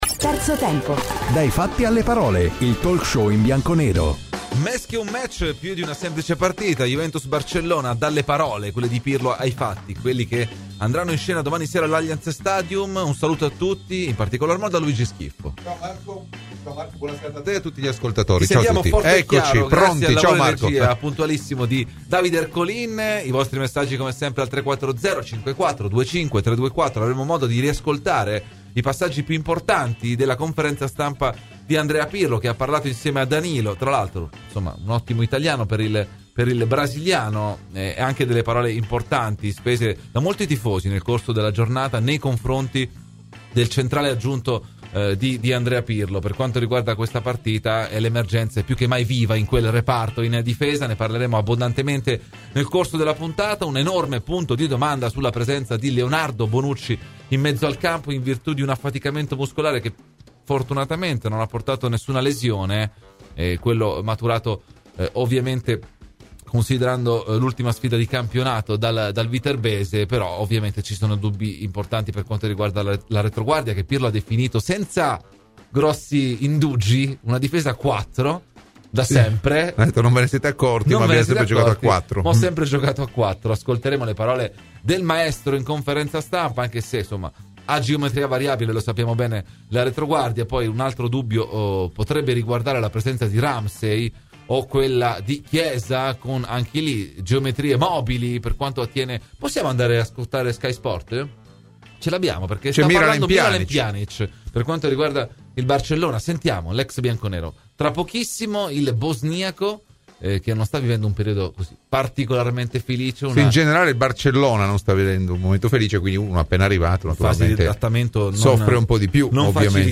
Ai microfoni di Radio Bianconera